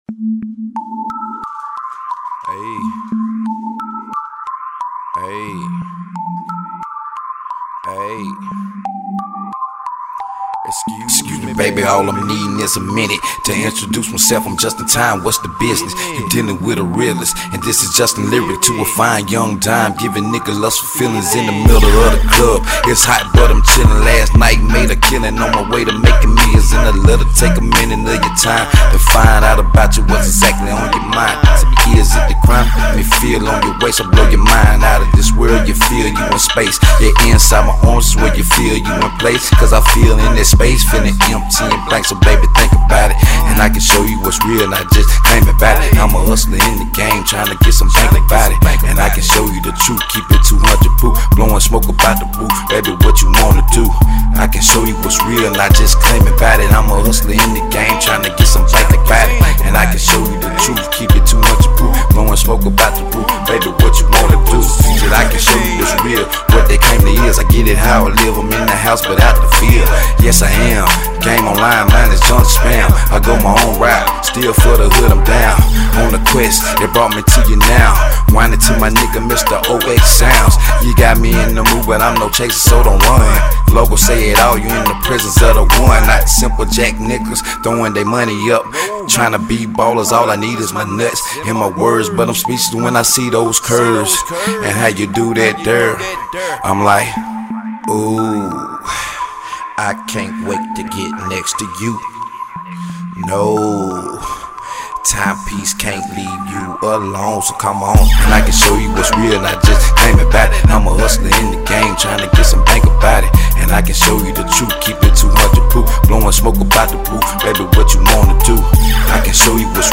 Hiphop
Club banger